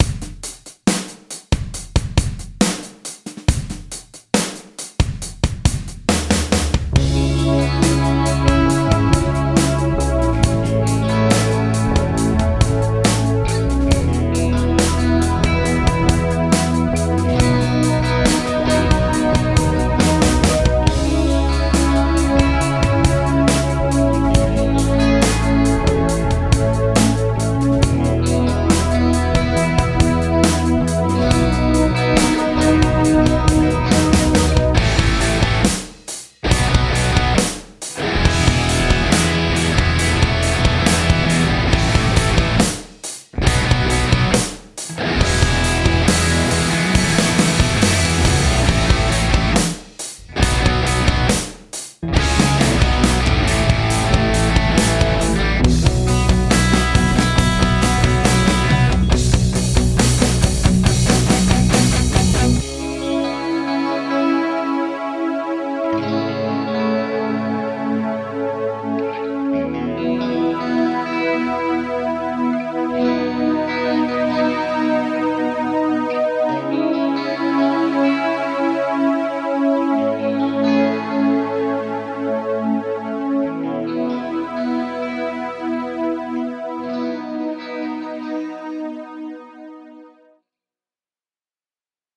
the backing track (click the link to download the mp3 file)
Jam_Like_A_BOSS_Competition_Backing_Track.mp3